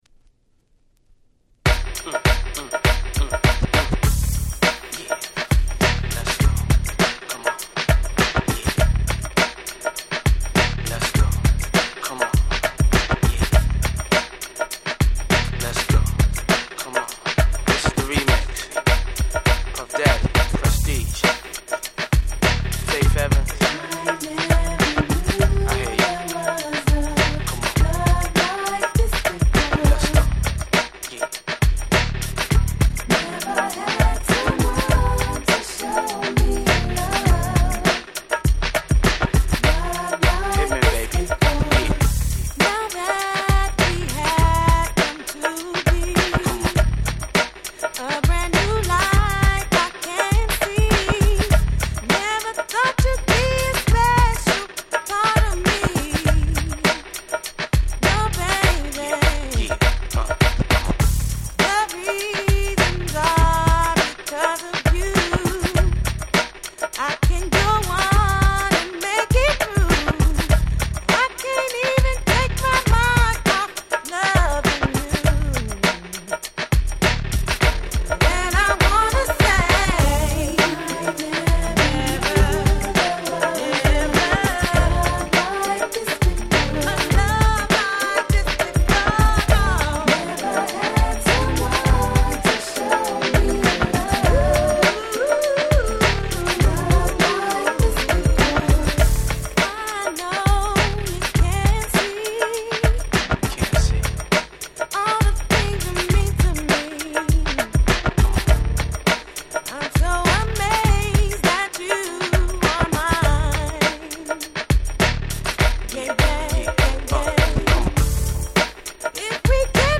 98' Super Hit R&B !!!!!